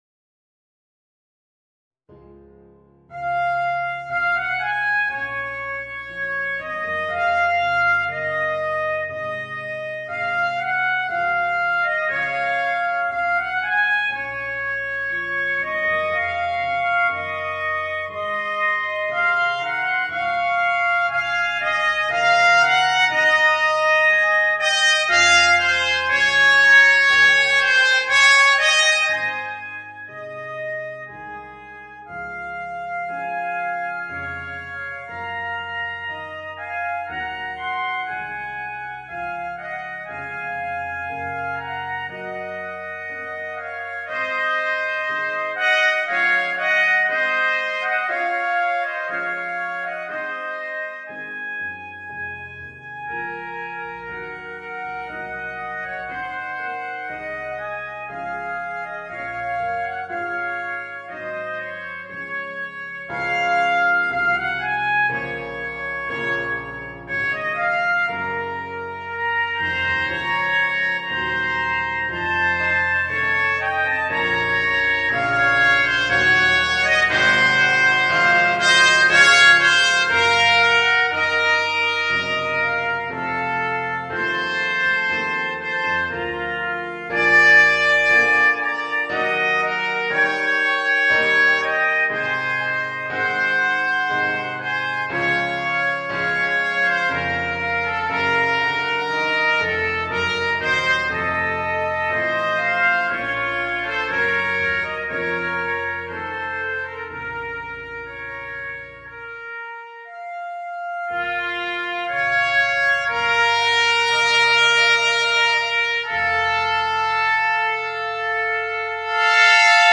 Voicing: 2 Piccolo Trumpets and Piano